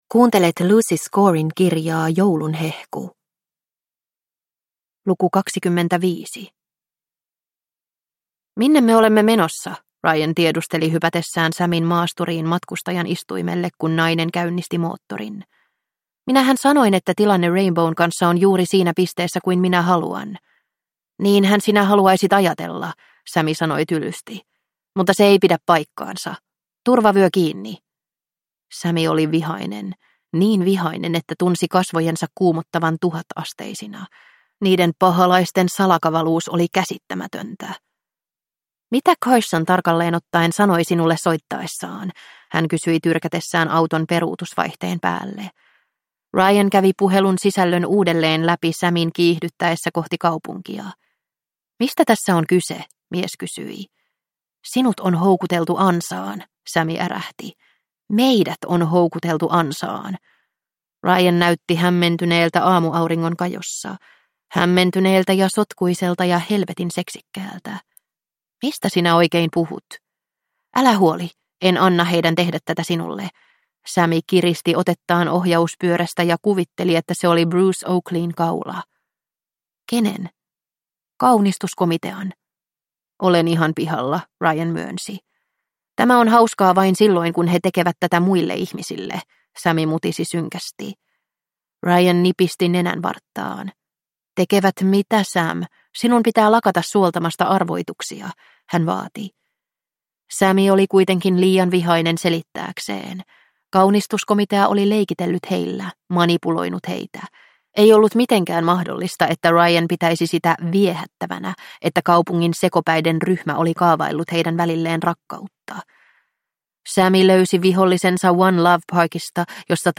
Joulun hehku - Luukku 21 – Ljudbok